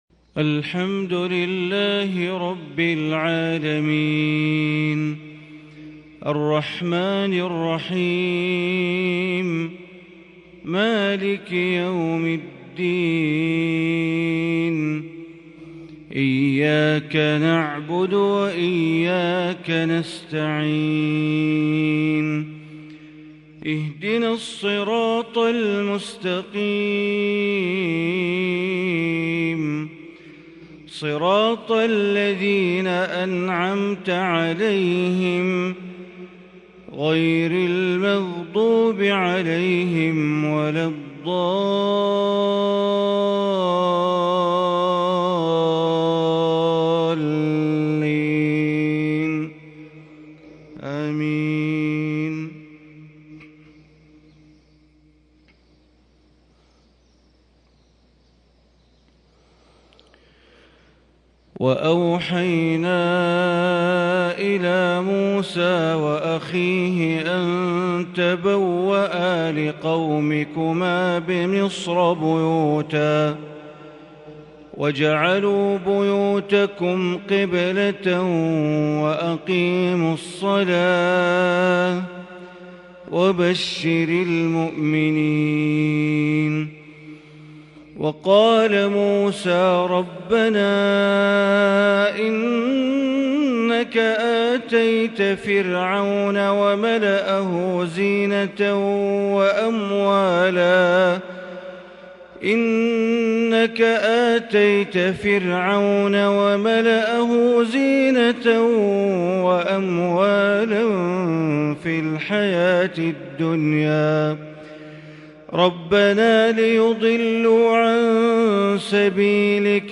صلاة الفجر ٣-٦-١٤٤٢هـ من سورة يونس > 1442 هـ > الفروض - تلاوات بندر بليلة